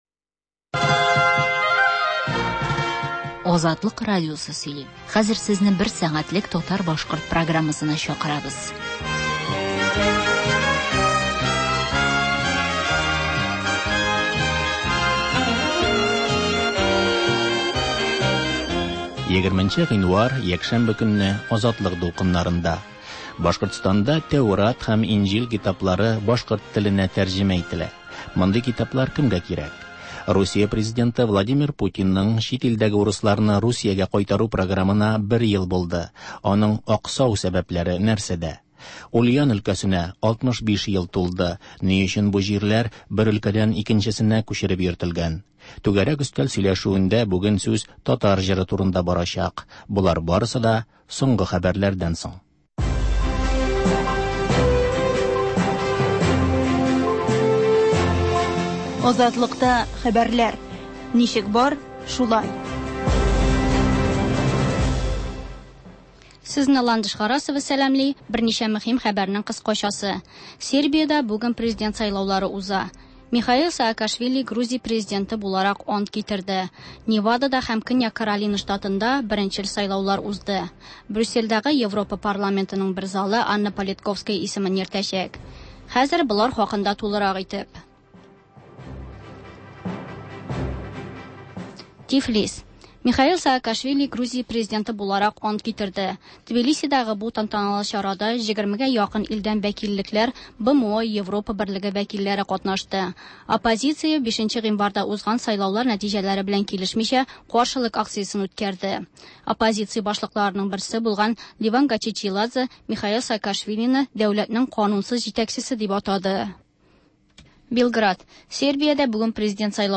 Азатлык радиосы бар атнага күз сала - соңгы хәбәрләр - Башкортстаннан атналык күзәтү - түгәрәк өстәл артында сөйләшү